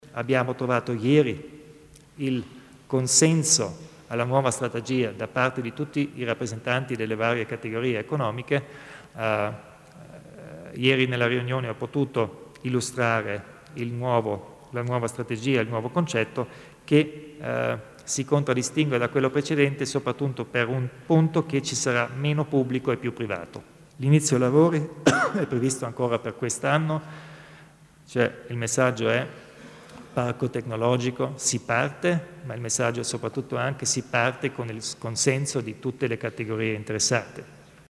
Il Presidente Kompatscher spiega i prossimi passi per la realizzazione del parco tecnologico
Ieri sera (7 luglio) l'incontro con i rappresentanti delle categorie economiche per spiegare il nuovo concetto che sta alla base del nascente Parco Tecnologico, questa mattina il via libera in Giunta provinciale, a seguire l'annuncio durante la conferenza stampa del martedì.